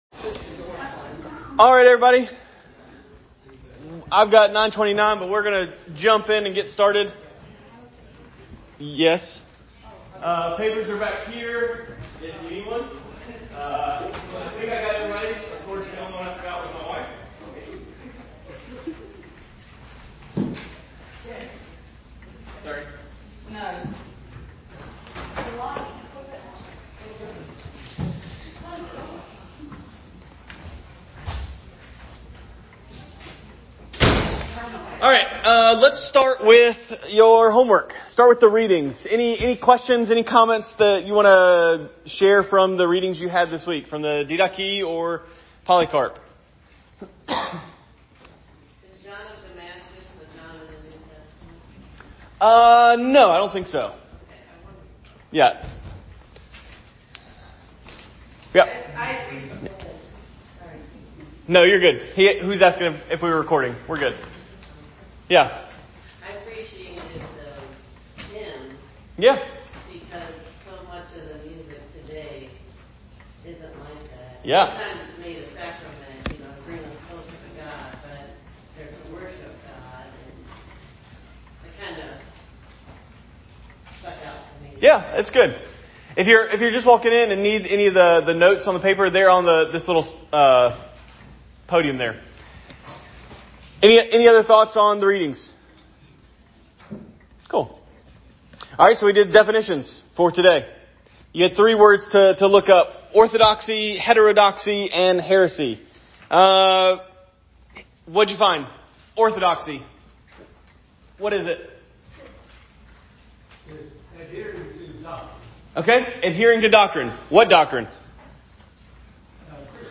Sunday School Lesson Topic: Church History